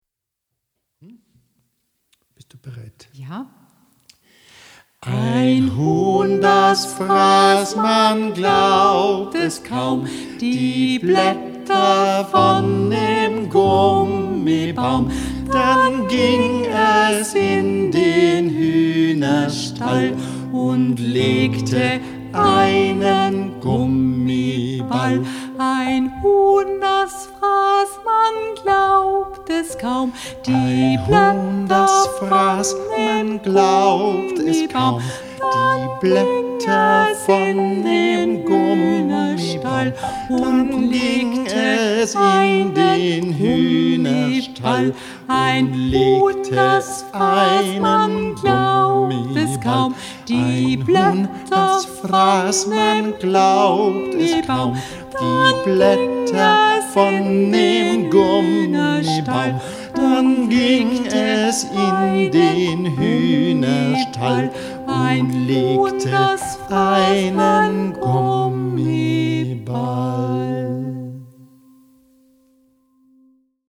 Klavier
Zuerst einstimmig, dann im Kanon gesungen.